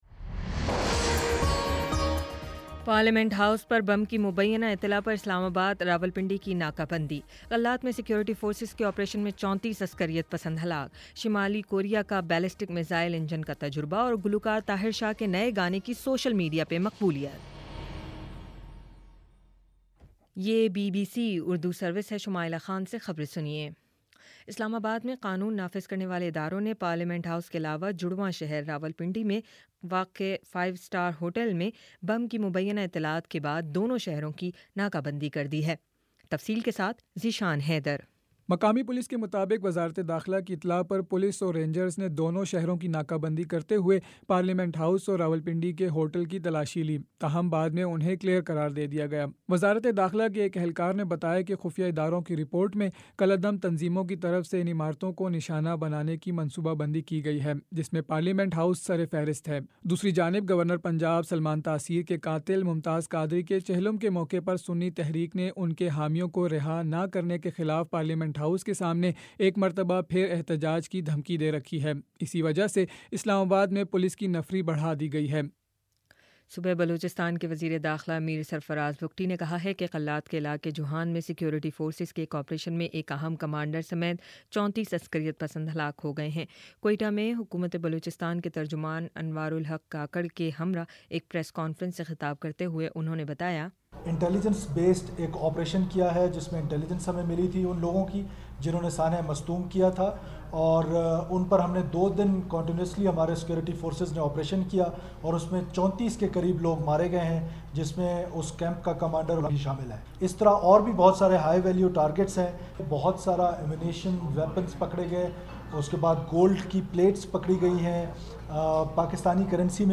اپریل 09 : شام چھ بجے کا نیوز بُلیٹن